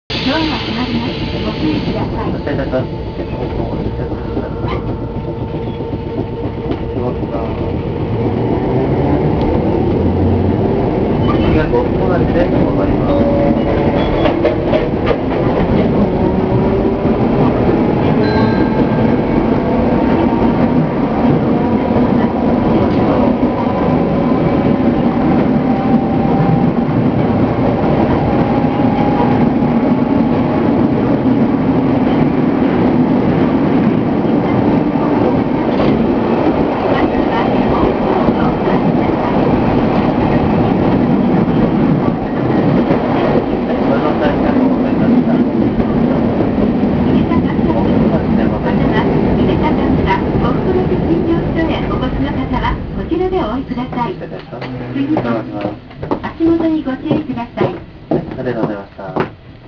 〜車両の音〜
・1200形走行音
【Ａ系統】祇園橋〜呉服町（1分00秒：325KB）
勿論吊り掛け式です。路面電車の割に少々重い音がする印象。